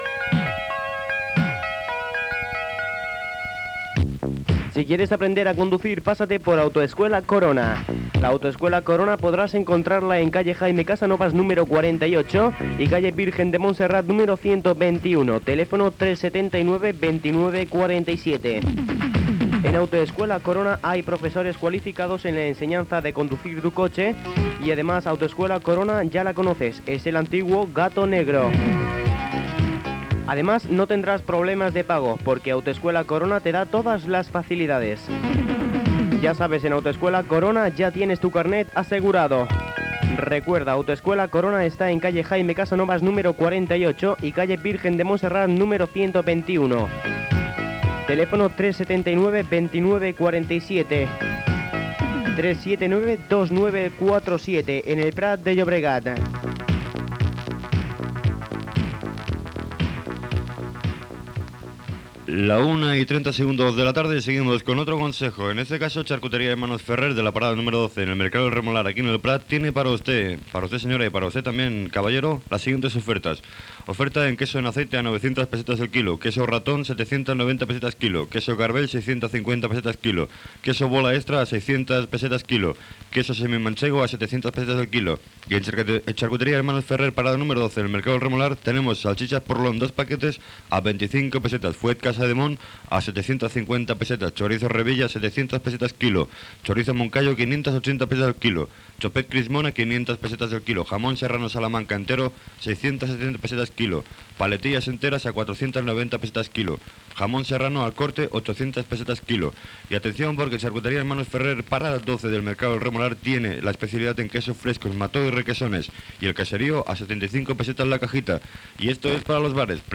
Publicitat, identificació i tema musical.
FM